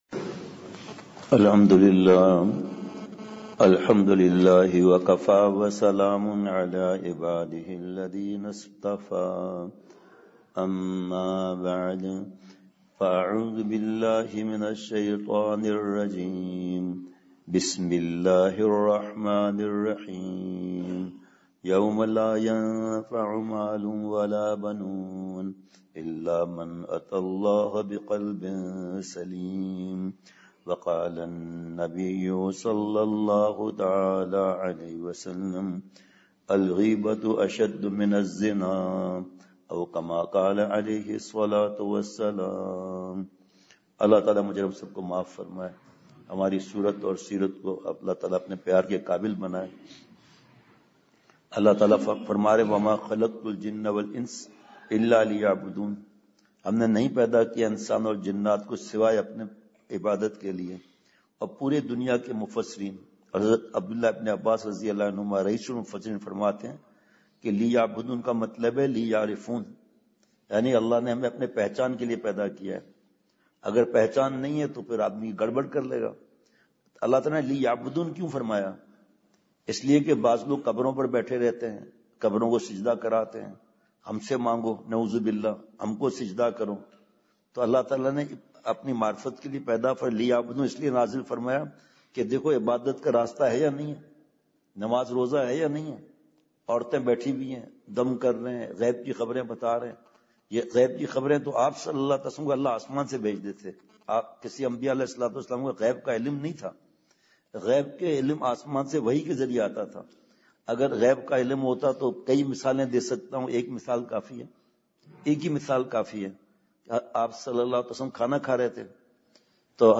Please download the file: audio/mpeg مجلس محفوظ کیجئے اصلاحی مجلس کی جھلکیاں بمقام :۔ مدنی مسجد حبیب آباد۔پشاور